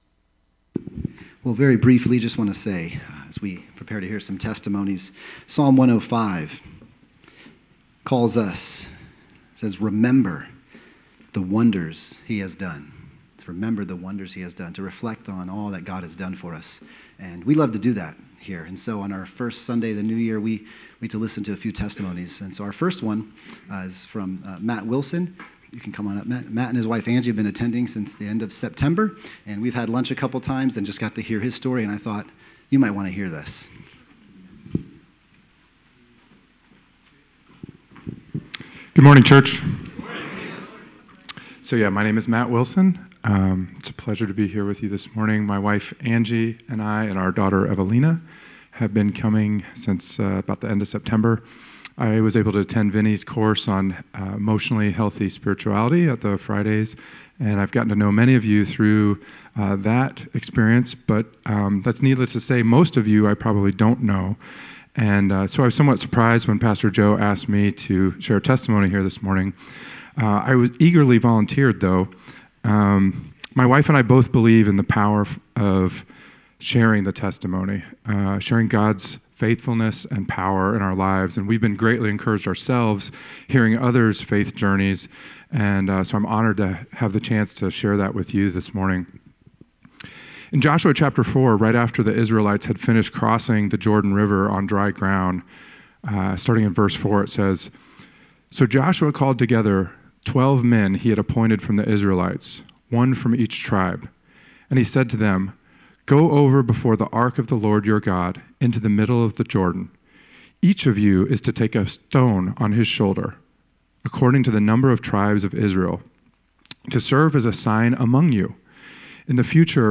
Series: Celebrate Recovery Member Testimonies Changed Lives